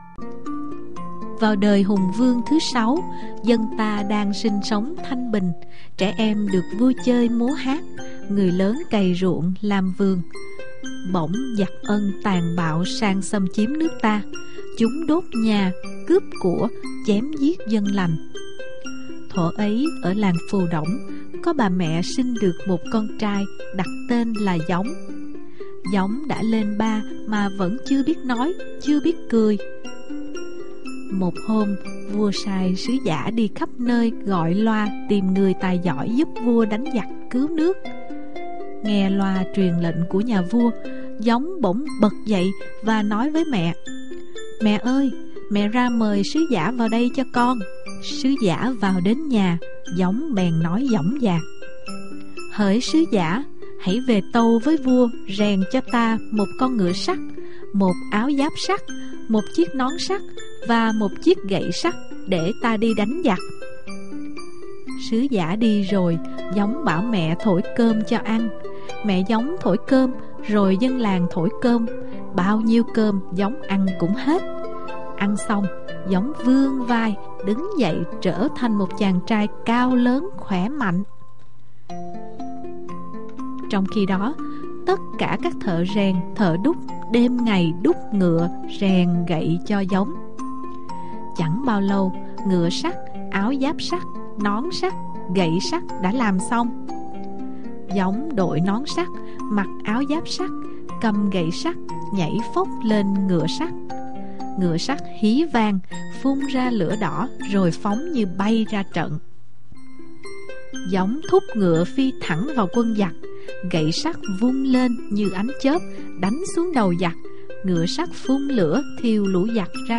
Sách nói | Thánh Gióng - Phù Đổng thiên vương